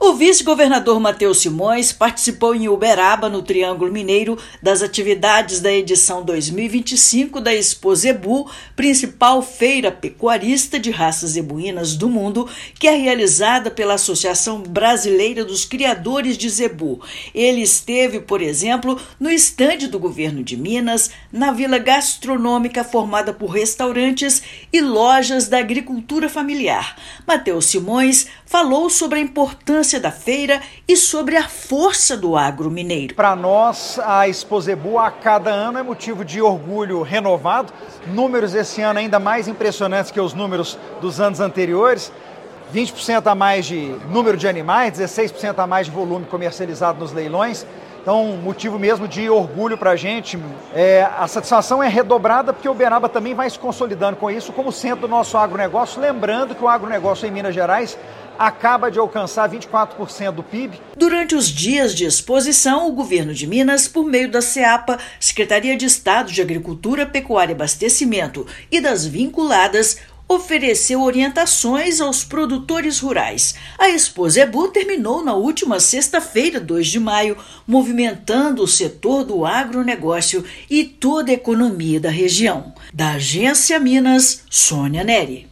Na edição de 90 anos da exposição, Governo de Minas marca presença com estande onde produtores rurais recebem orientações sobre apoio técnico oferecido pelo Estado. Ouça matéria de rádio.